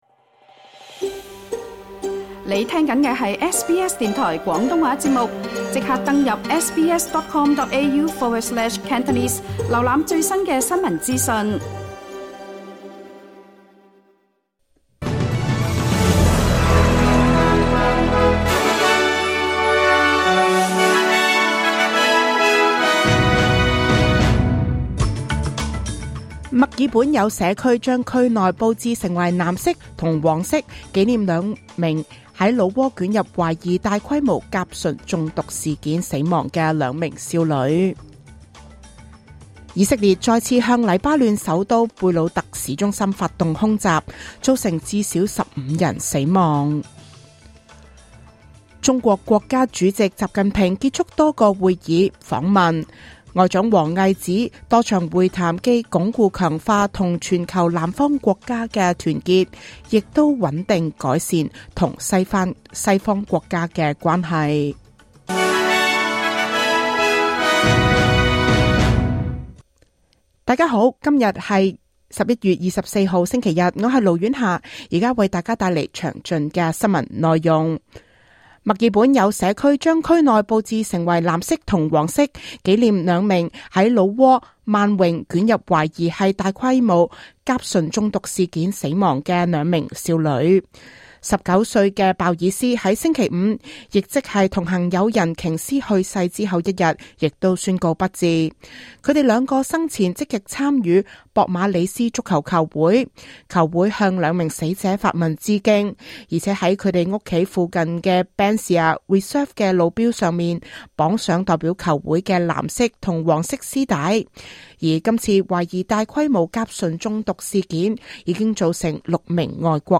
2024 年 11 月 24 日 SBS 廣東話節目詳盡早晨新聞報道。